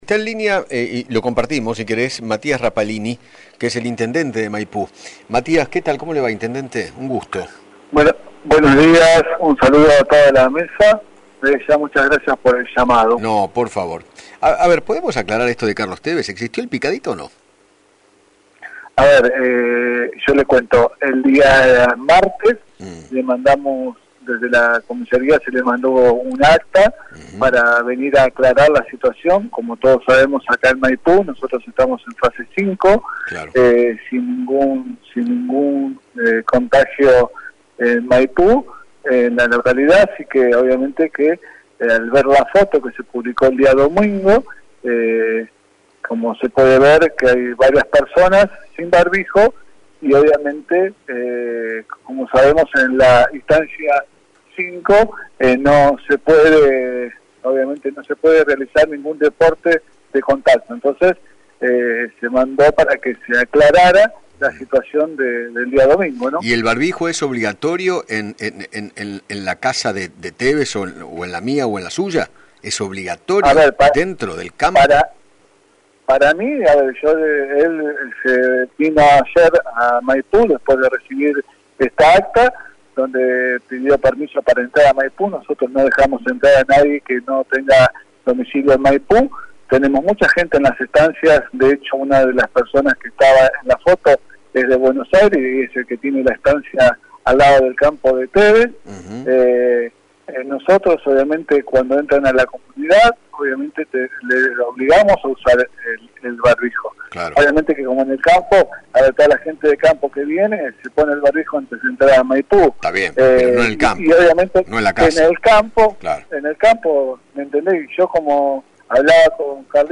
Matías Rapallini, Intendente de Maipú, dialogó con Eduardo Feinmann, sobre la denuncia por el supuesto picadito de fútbol que se jugó en el campo que tiene Carlos Tévez en dicho municipio, a raíz de una imagen que se volvió viral en la que se ve al futbolista junto a varias personas sin distanciamiento ni barbijos.